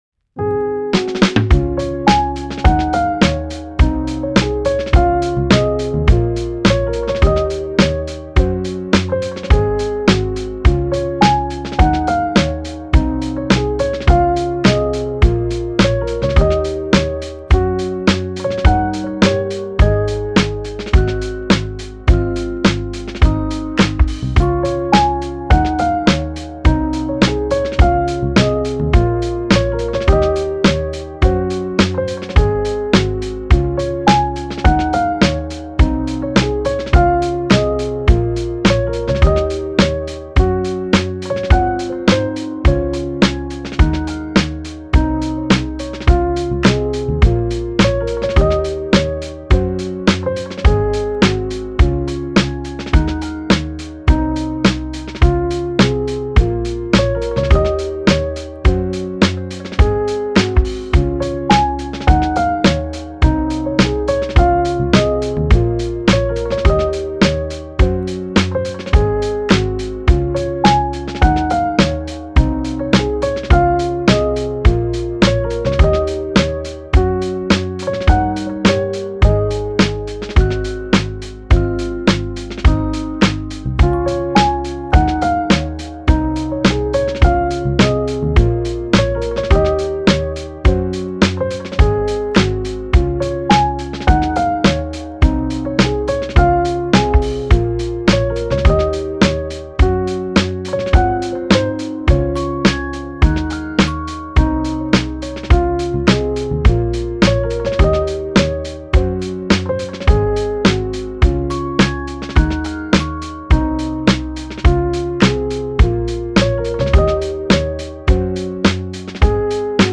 フリーBGM
チル・穏やか